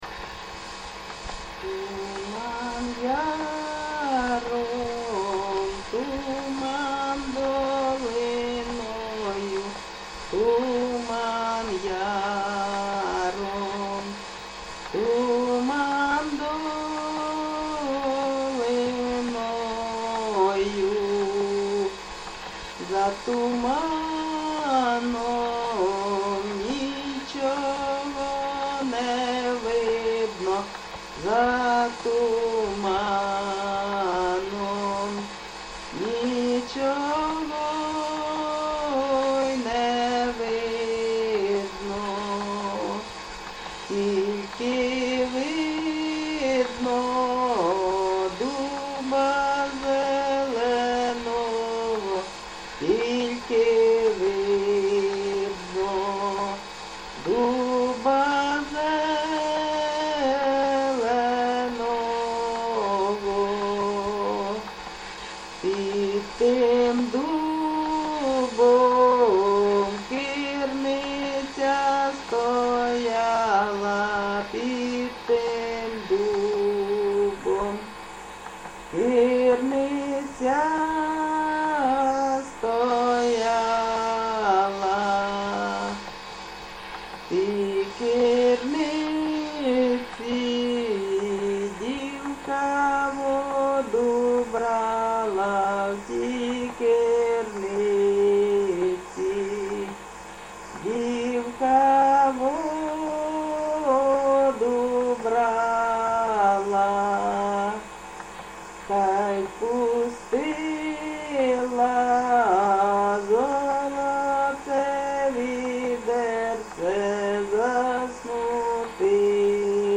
ЖанрПісні з особистого та родинного життя
Місце записус. Олександро-Калинове, Костянтинівський (Краматорський) район, Донецька обл., Україна, Слобожанщина